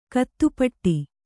♪ kaattupaṭṭi